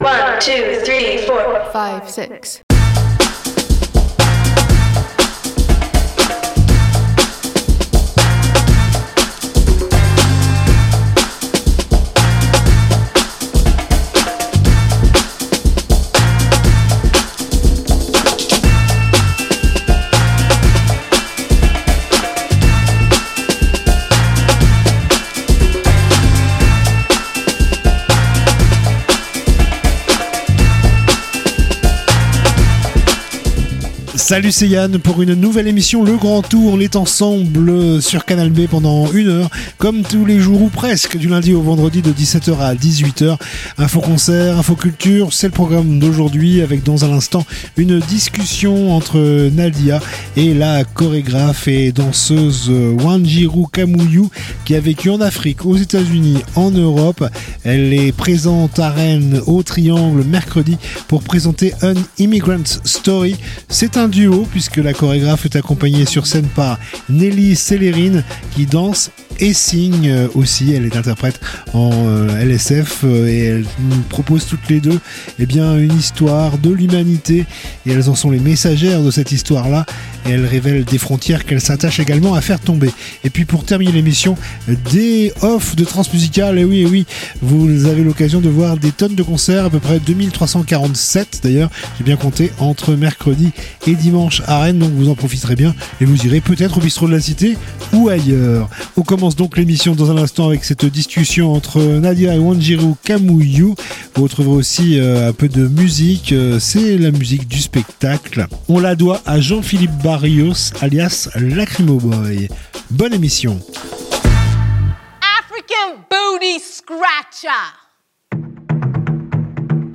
itv